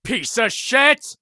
gun_jam_2.ogg